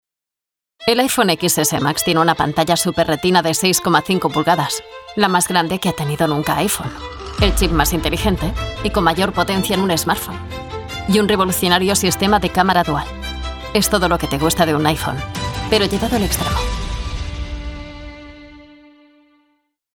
Neumann U87 + Studiobricks.
kastilisch
Sprechprobe: Sonstiges (Muttersprache):